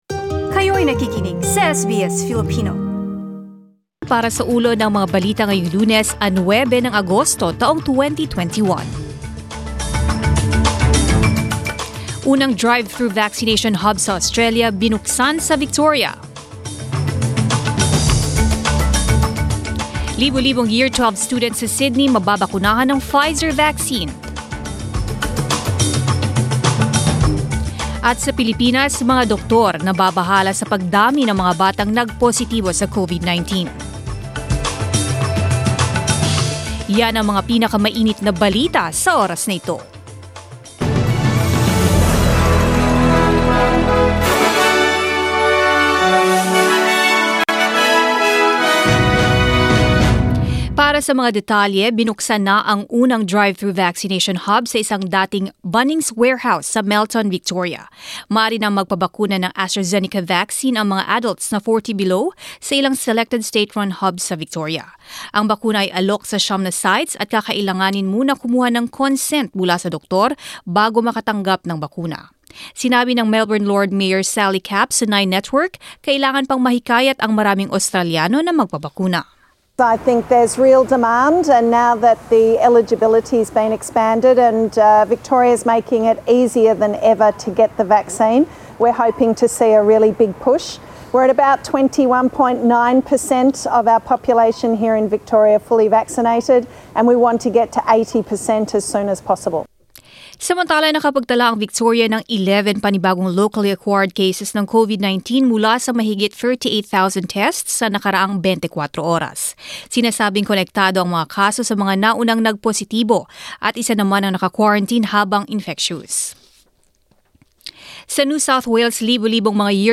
Mga Balita ngayong ika-9 ng Agosto